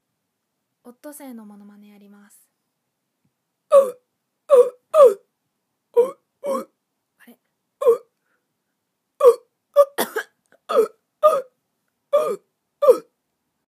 オットセイのモノマネ